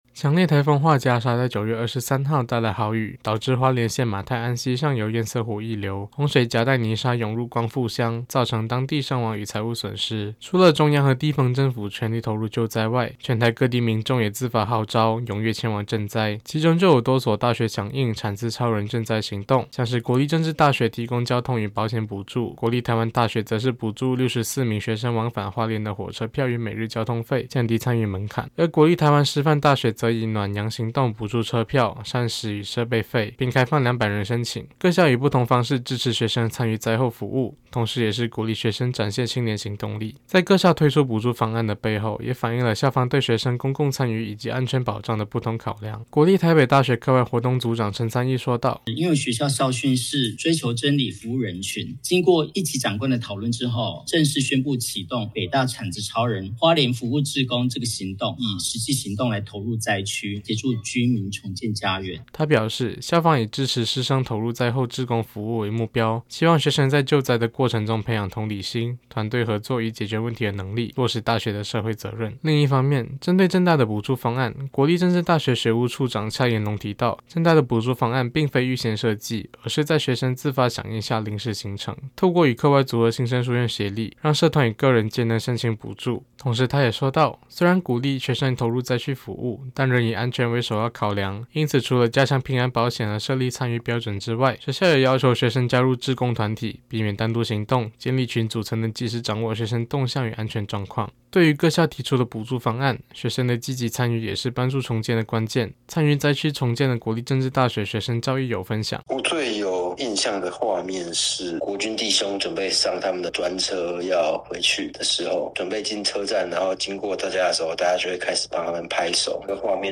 政大之聲實習廣播電台-新聞專題